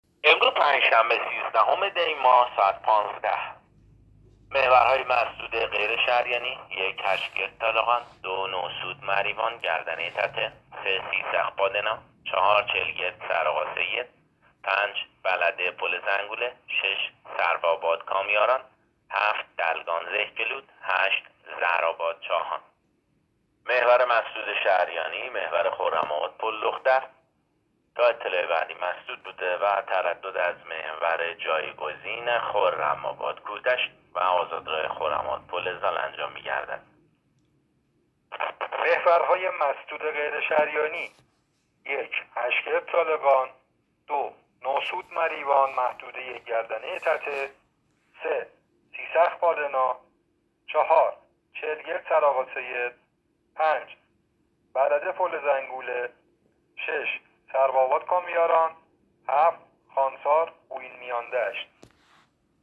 گزارش رادیو اینترنتی از آخرین وضعیت ترافیکی جاده‌ها تا ساعت ۱۵ سیزدهم دی؛